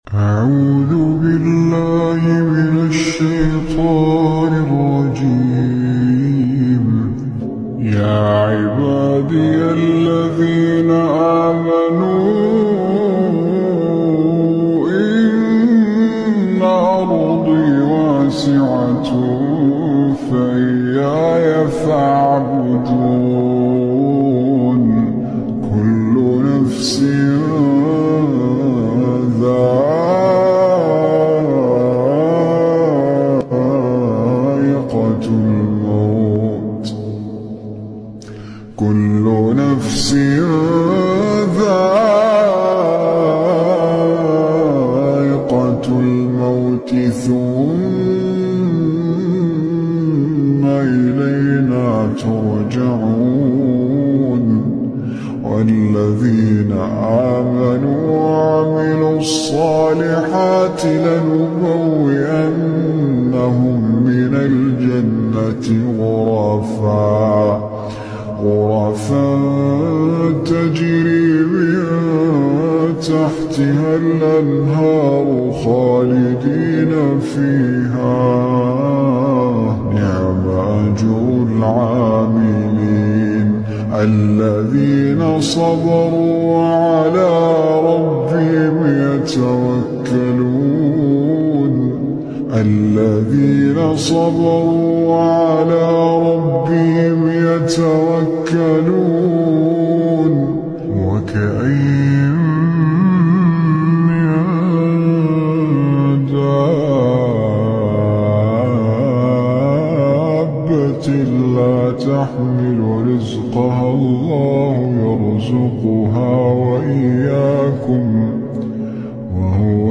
SLOWED+REWERB